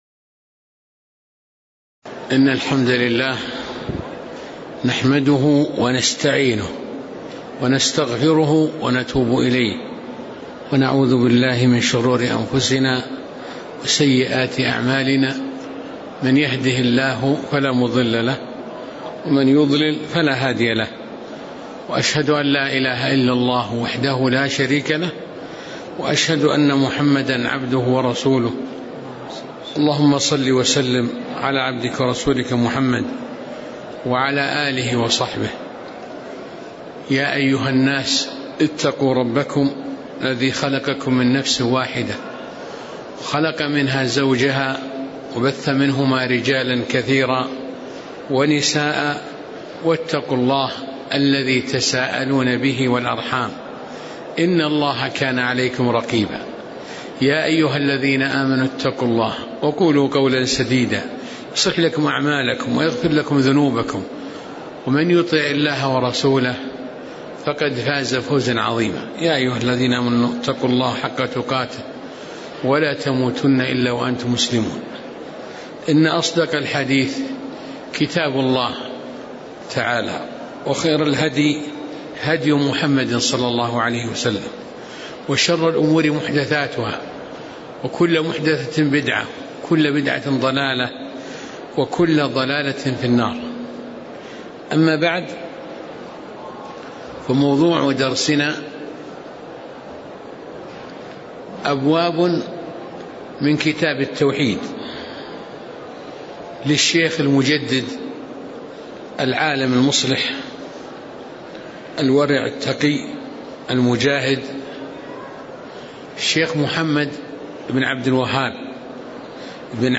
تاريخ النشر ١ رجب ١٤٣٨ هـ المكان: المسجد النبوي الشيخ